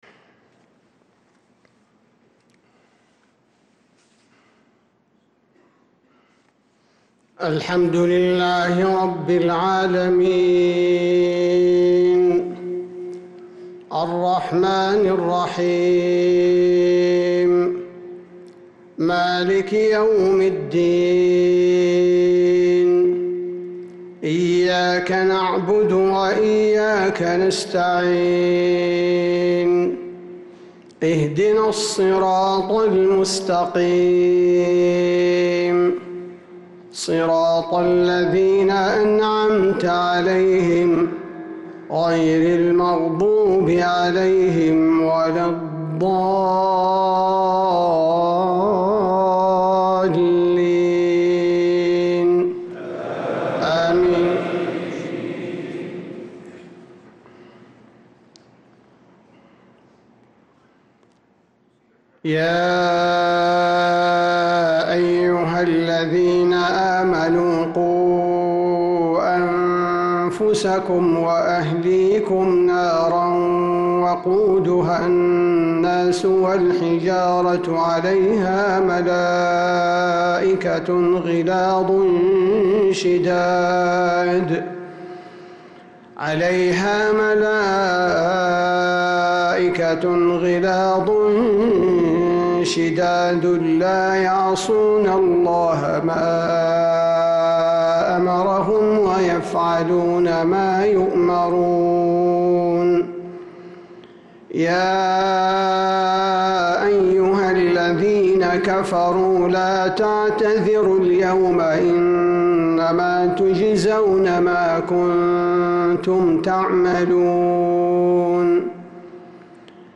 صلاة العشاء 2-8-1446هـ | من سورة التحريم 6-12 | Isha prayer from Surah at-Tahrim | 1-2-2025 > 1446 🕌 > الفروض - تلاوات الحرمين